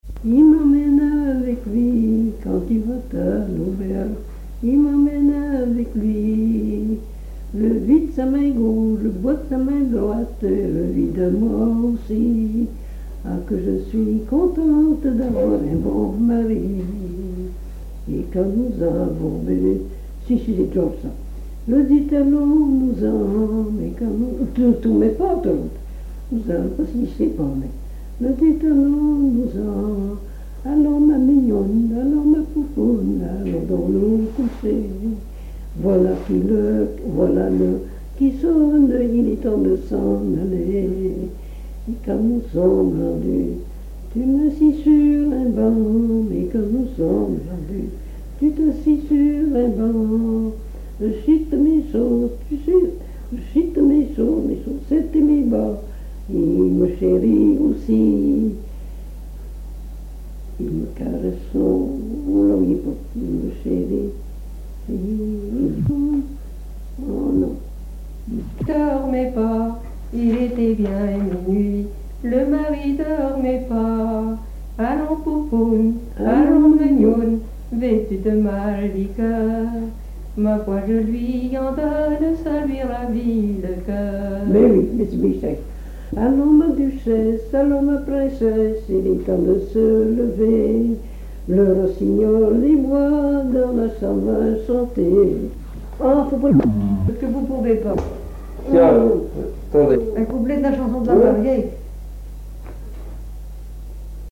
Genre dialogue
Pièce musicale inédite